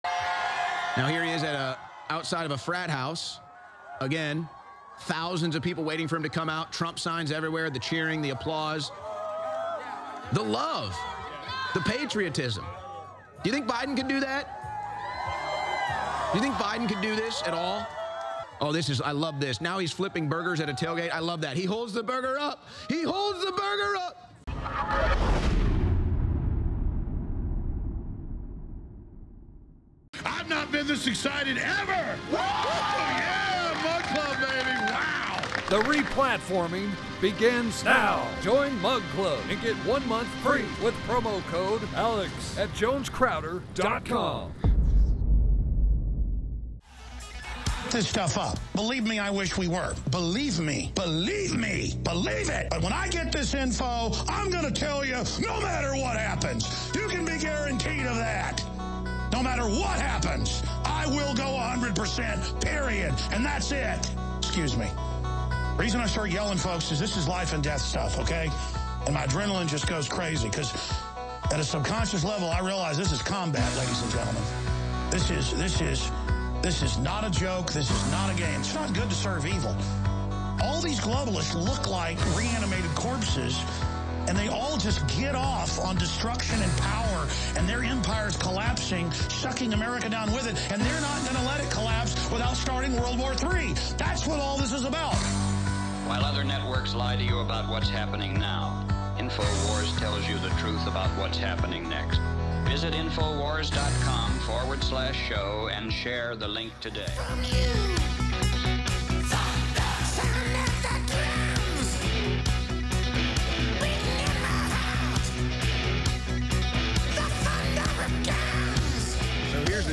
Donald Trump Has Epic Weekend With Massive Crowd At South Dakota Rally Then Raucous Applause At College Football Game